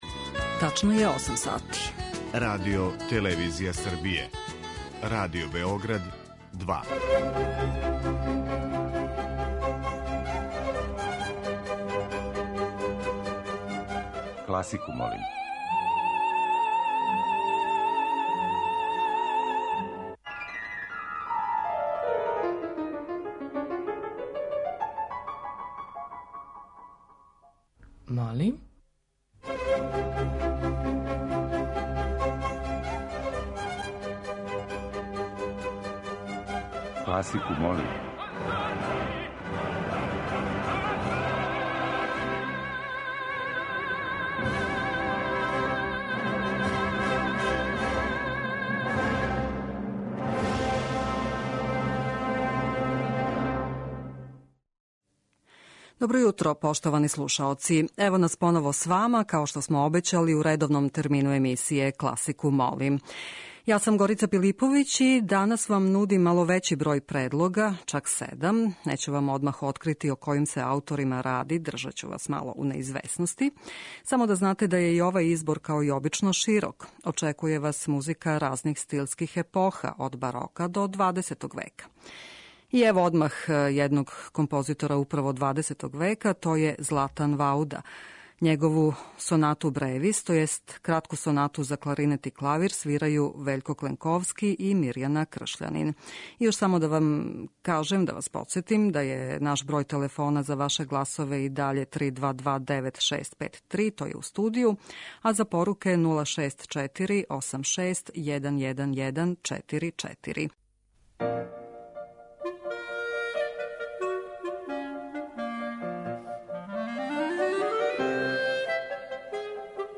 Избор за недељну топ-листу класичне музике Радио Београда 2
преузми : 21.42 MB Класику молим Autor: Група аутора Стилски и жанровски разноврсни циклус намењен и широком кругу слушалаца који од понедељка до четвртка гласају за топ листу недеље.